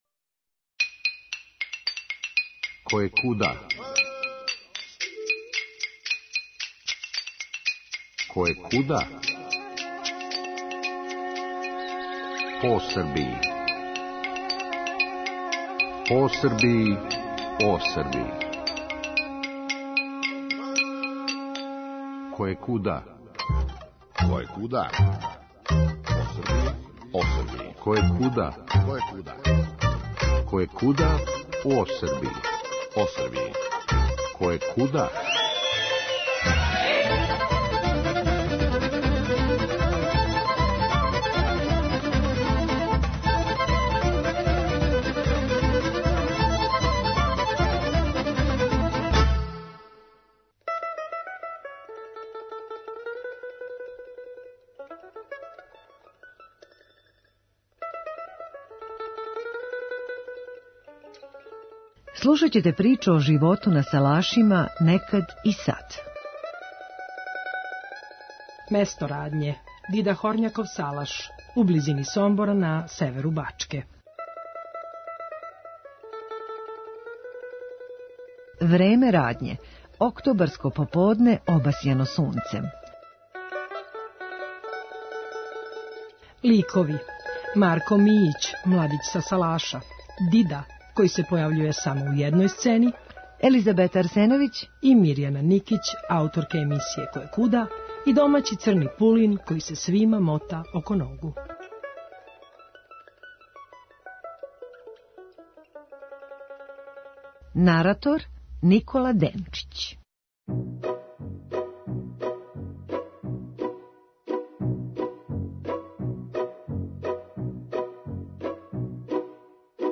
Заједно вас водимо у шетњу овим занимљивим салашарским имањем.